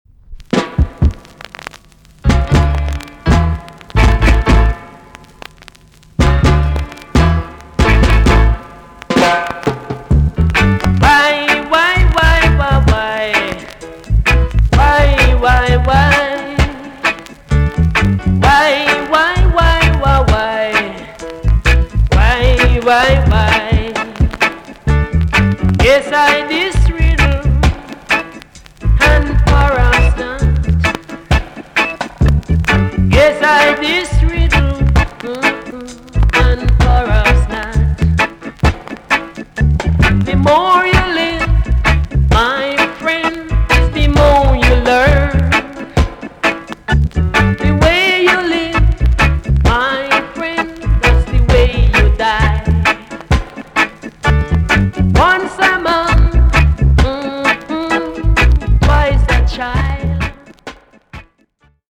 TOP >SKA & ROCKSTEADY
VG+ 少し軽いプチノイズが入ります。
NICE ROCK STEADY TUNE!!